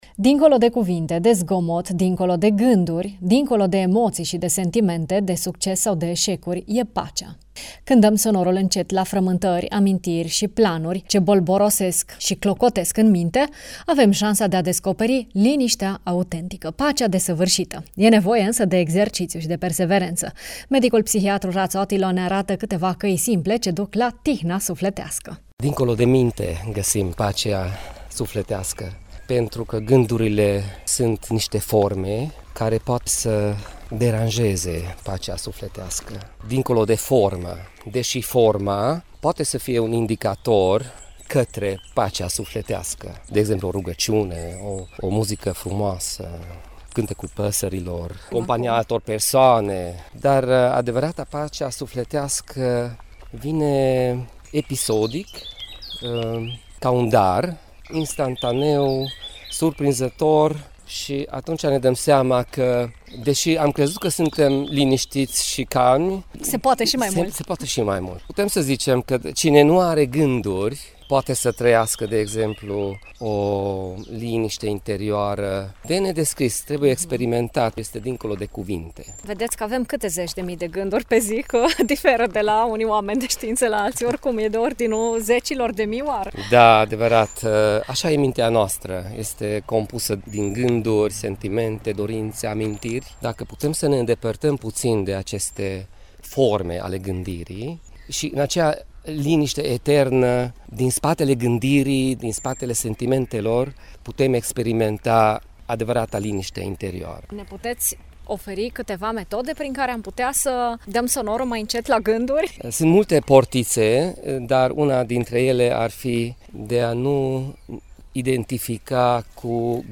medic psihiatru